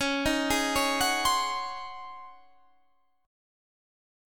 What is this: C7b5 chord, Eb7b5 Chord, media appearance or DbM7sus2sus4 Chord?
DbM7sus2sus4 Chord